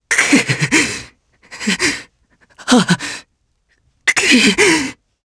Theo-Vox_Sad_jp.wav